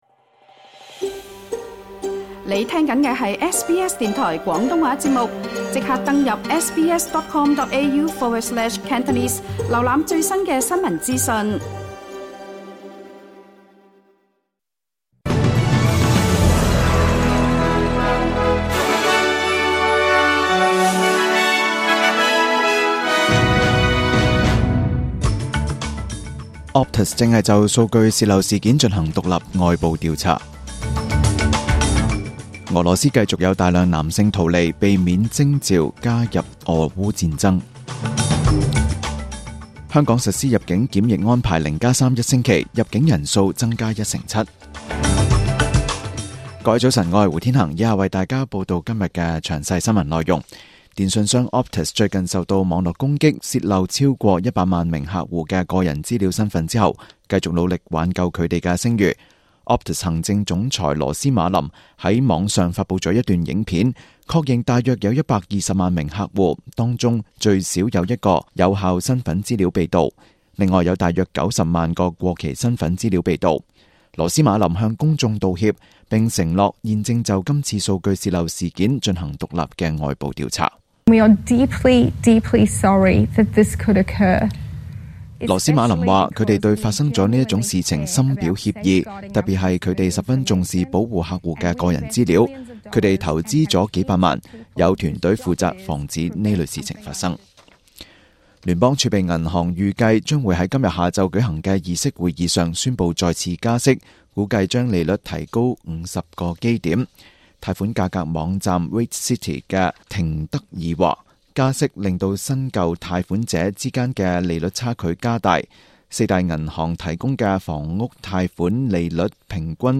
SBS 廣東話節目中文新聞 Source: SBS / SBS News